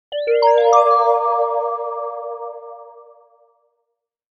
Futuristic UI Alert Sound Effect
Experience a sleek, modern notification sound with a smooth digital tone. This futuristic alert sound effect adds a clean and professional touch to any app, smartphone, or interface.
Futuristic-ui-alert-sound-effect.mp3